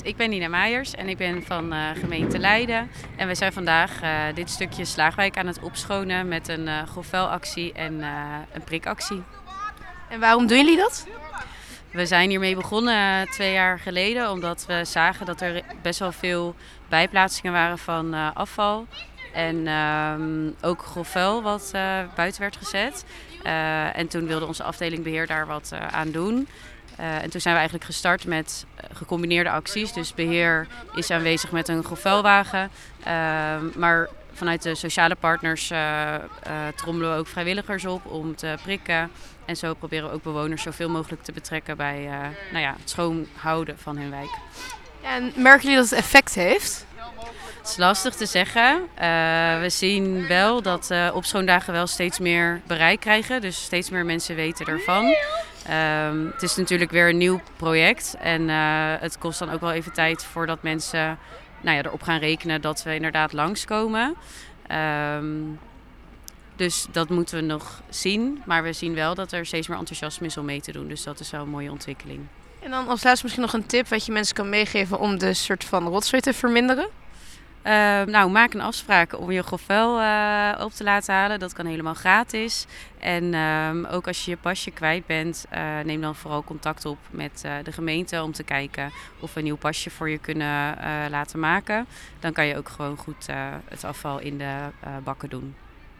Van 13.00 tot 15.00 uur gaan zij aan de slag tijdens de maandelijkse opschoondag in de Slaaghwijk in de Merenwijk.